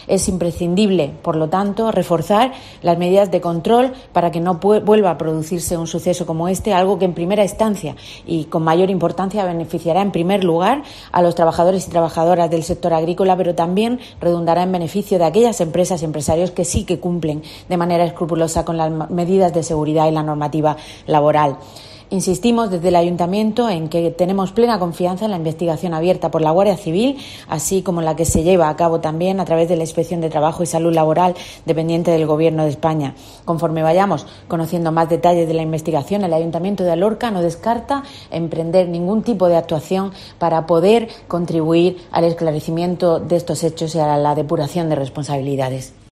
Isabel Casalduero, portavoz equipo de gobierno